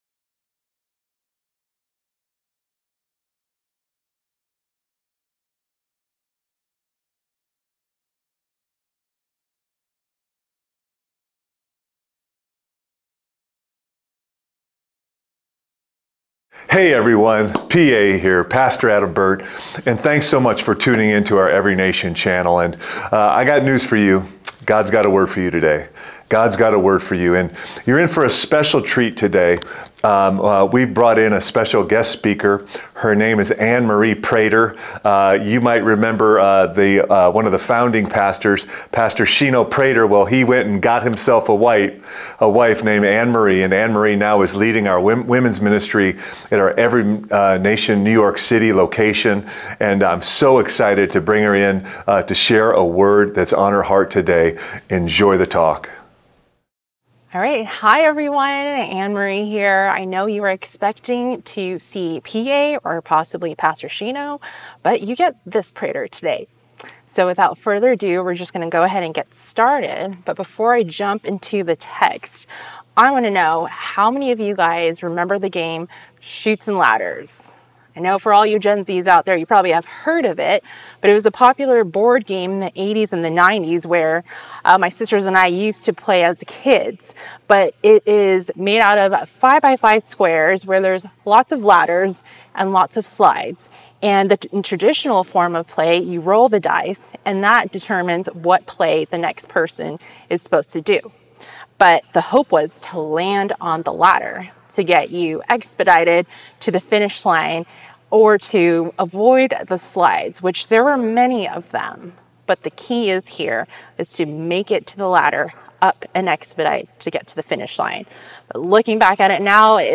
ENCNJ Sermon 7/7/24